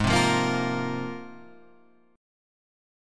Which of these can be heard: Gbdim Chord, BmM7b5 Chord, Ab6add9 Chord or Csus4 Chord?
Ab6add9 Chord